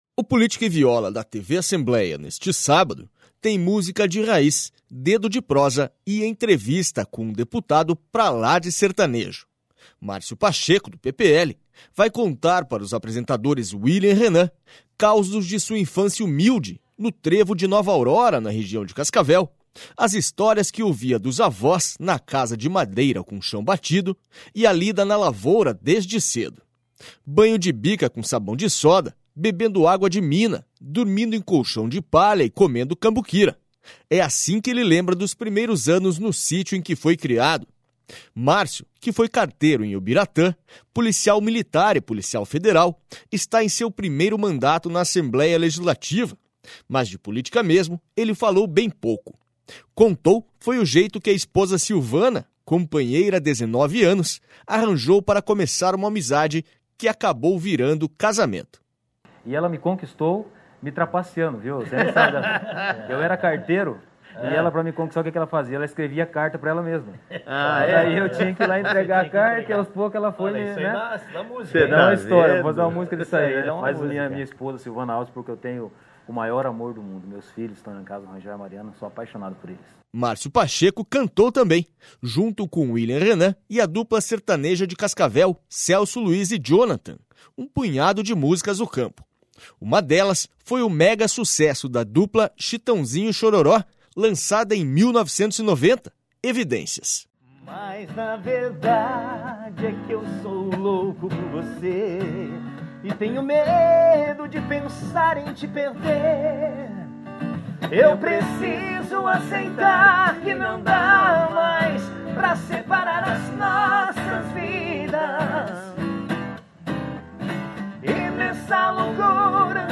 O Política e Viola da TV Assembleia neste sábado tem música de raiz, dedo de prosa e entrevista com um deputado pra lá de sertanejo.